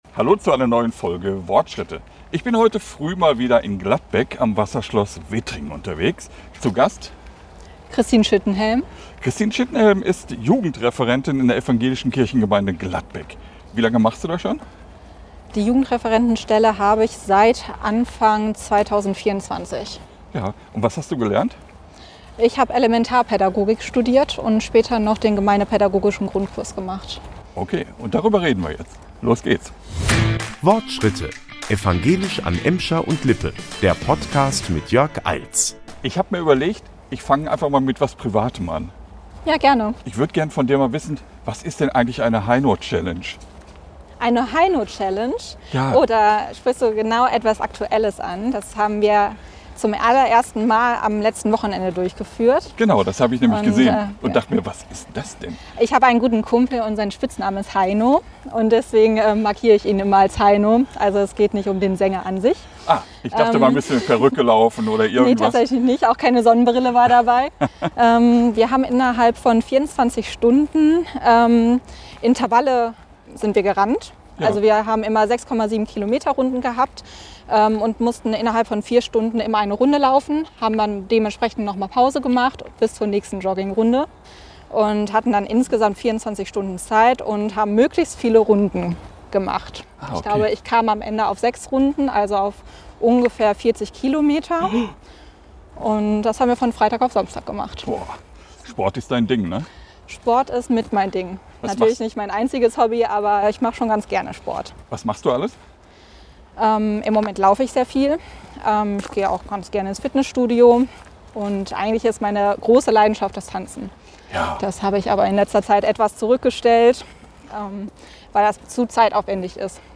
Kurz vor der Abfahrt hat sie mit uns noch am Wasserschloss Wittringen WortSchritte gemacht. Wir beleuchten die Situation der kirchlichen Jugendarbeit, reden über eine Social Media-Nutzung ab 16 Jahre, so wie es die Politik vorgeschlagen hat, und erfahren ganz nebenbei noch ein paar persönliche Takte über die studierte Elementarpädagogin.